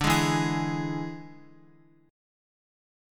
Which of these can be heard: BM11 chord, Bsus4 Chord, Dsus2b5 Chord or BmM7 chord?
Dsus2b5 Chord